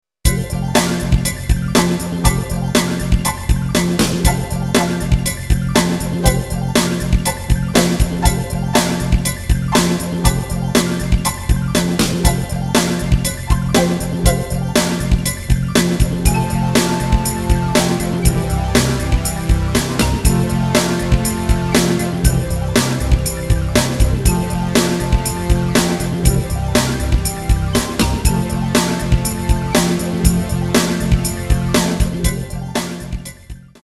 General MIDI based. Unfinished cover